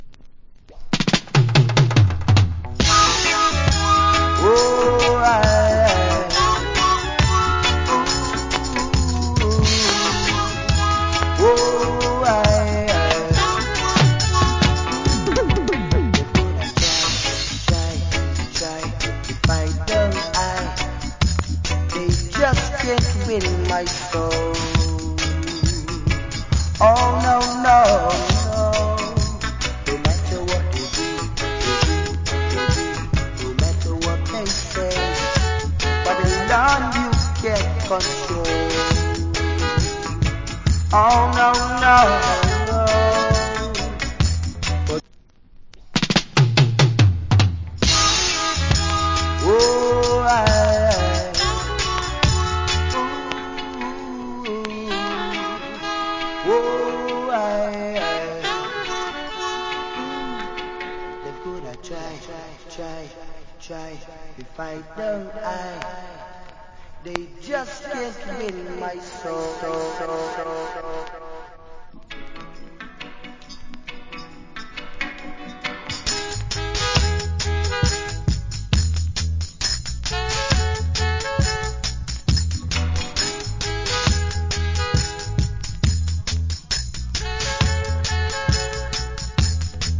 Wicked Roots Rock Vocal.